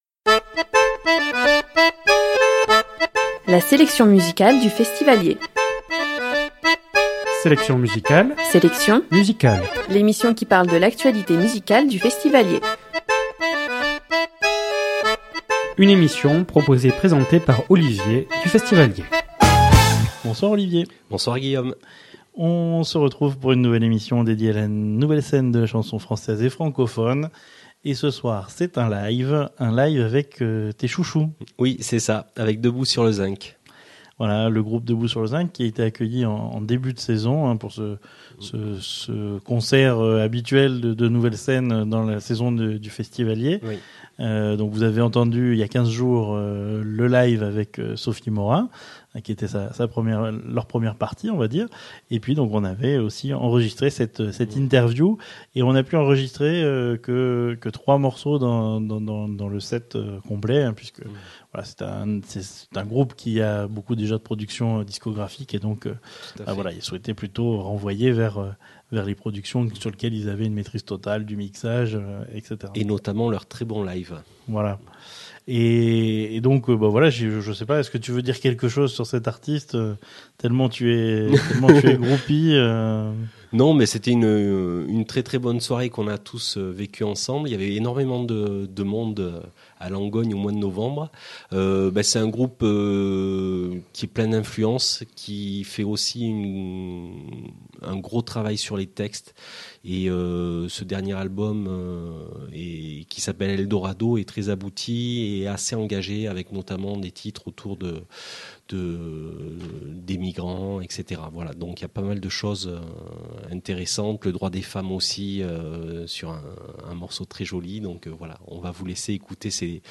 Séquence « Live » enregistrée dans le cadre de Festiv’Allier la saison Interview de Debout sur le zinc